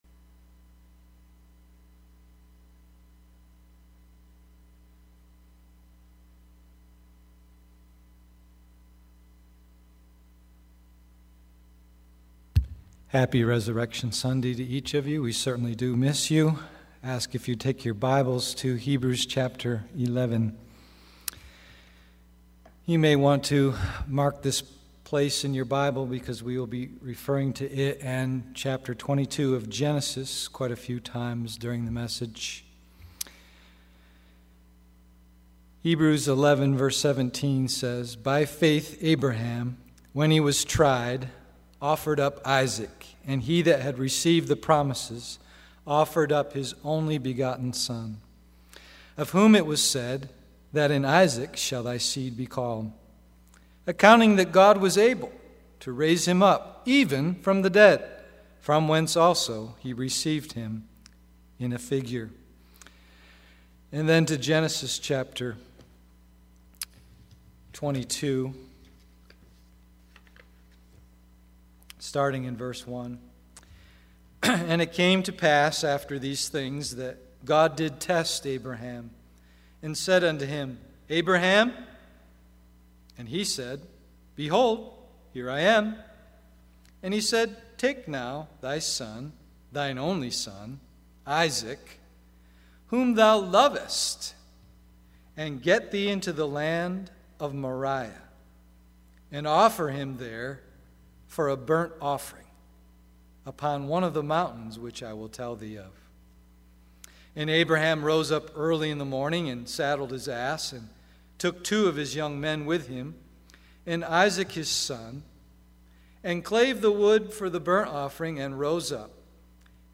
Resurrection Sunday